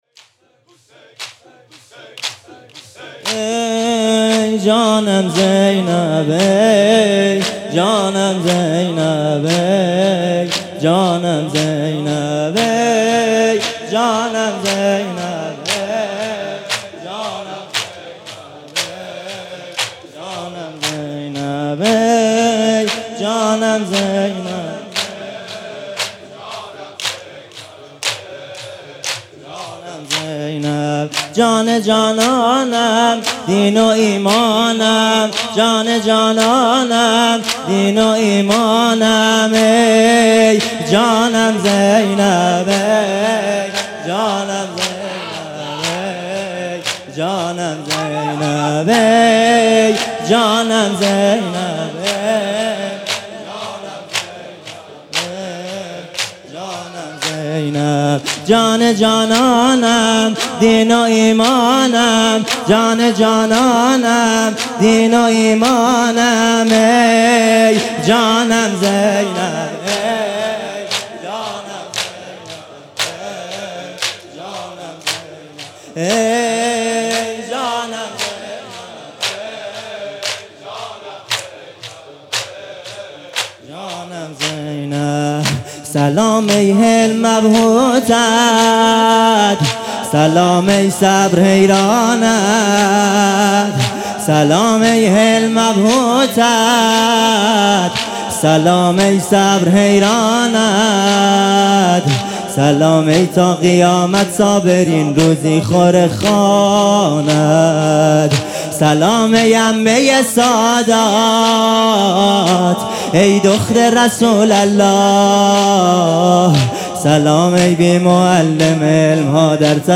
مناسبت : دهه اول صفر
قالب : زمینه